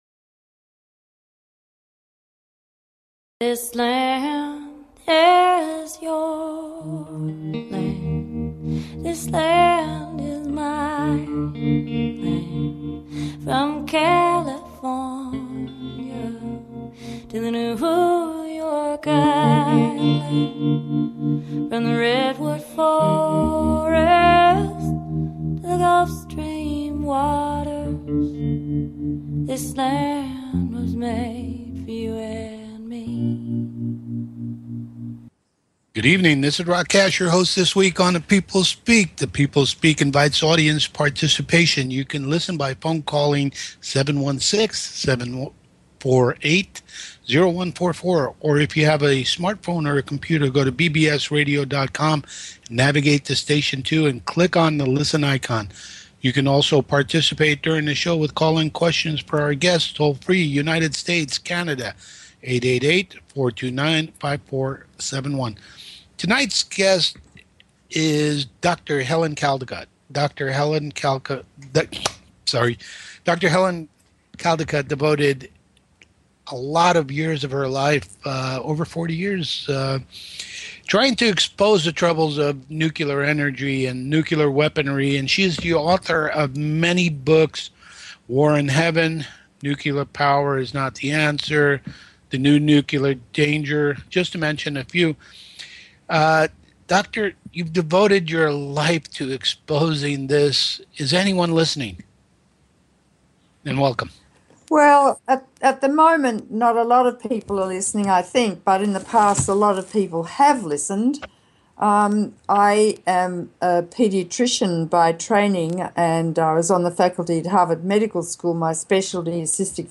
Guest, Dr Helen Caldicott